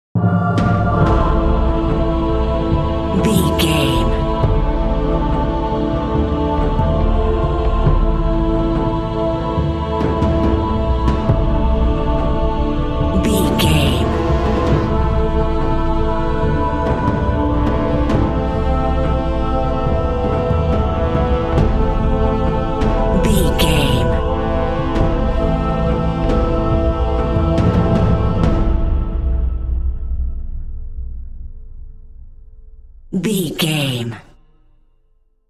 Thriller
Aeolian/Minor
synthesiser
drum machine
horns
percussion
ominous
suspense
haunting
creepy